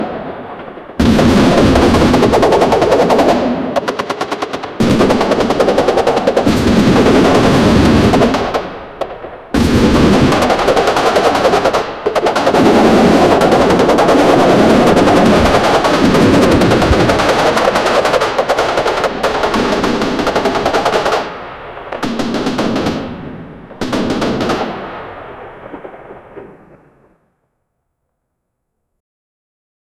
the-sound-of-a-medieval-qjh3tjdf.wav